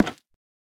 Minecraft Version Minecraft Version 1.21.5 Latest Release | Latest Snapshot 1.21.5 / assets / minecraft / sounds / block / nether_wood_trapdoor / toggle2.ogg Compare With Compare With Latest Release | Latest Snapshot
toggle2.ogg